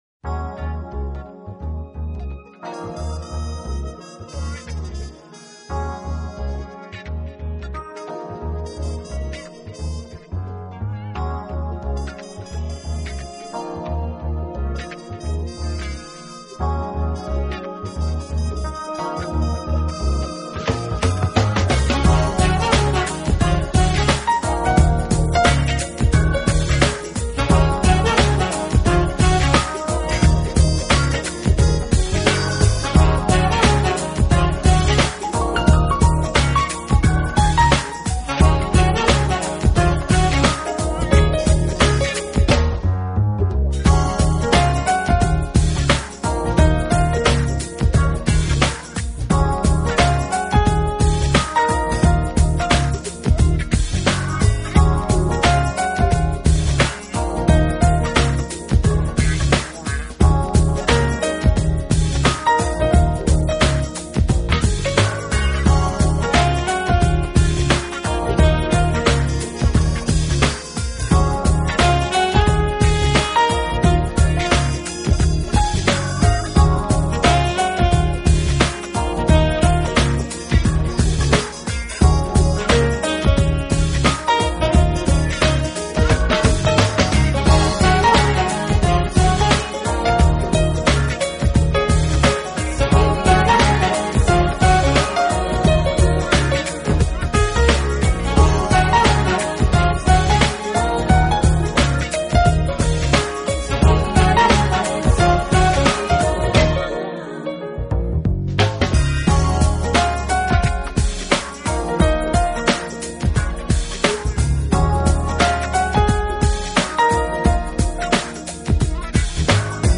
爵士钢琴
音乐风格：Jazz